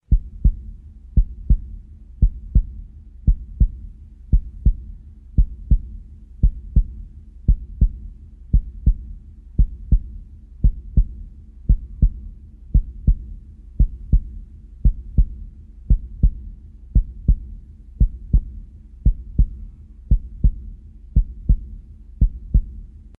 Звук сердцебиения в GrannynСердцебиение в игре GrannynЗвук биения сердца в Granny